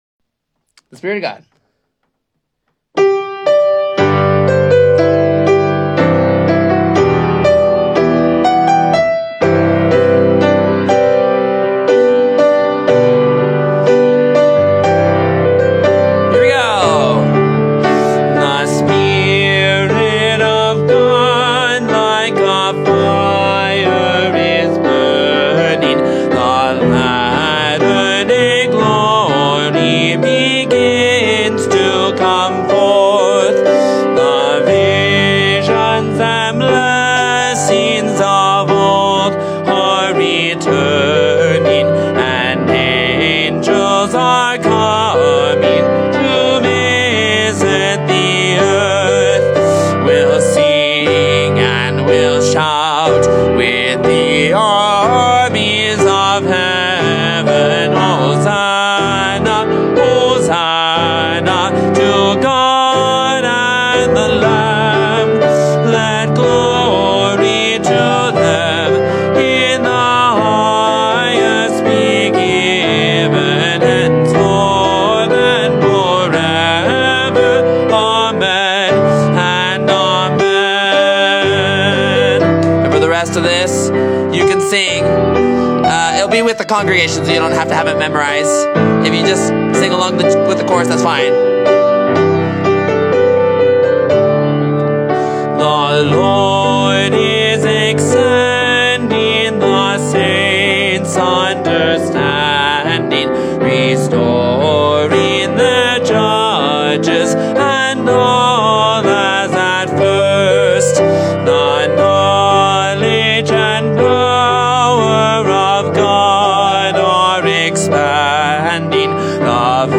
Children’s Choir, Congregation and Organ